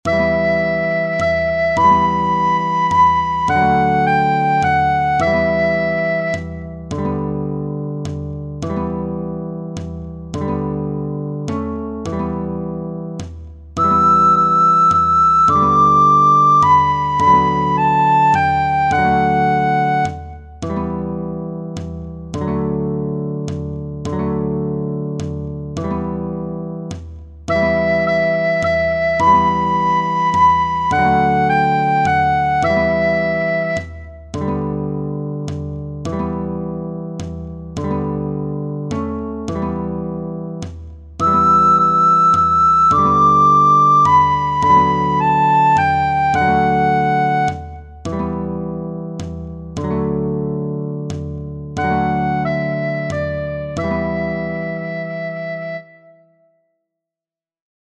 Scarborough Fair is a traditional British ballad whose lyrics can be traced back to a Scottish song from 1670.
Gif recorder  Do the same as with the previous score but pay attention to the silent bars.